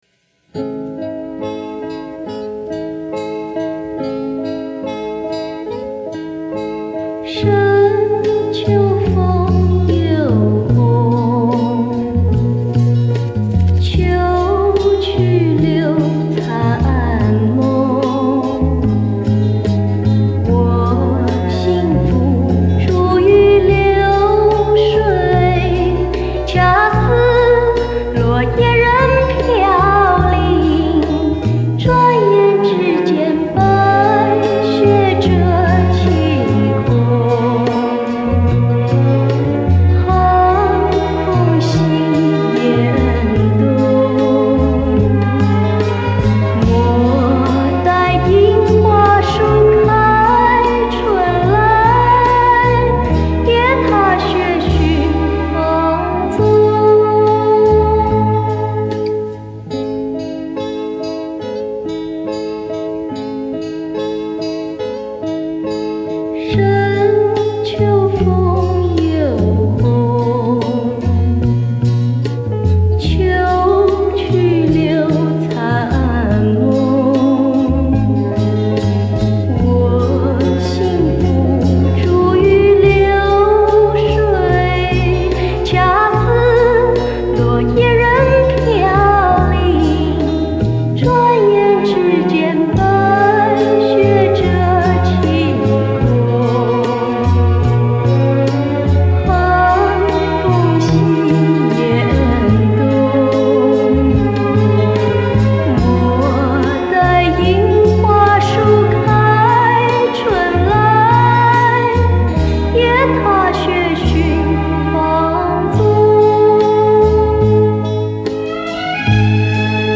甜甜柔柔的嗓音和纯纯的玉女形象，当年也颇受歌迷的喜爱。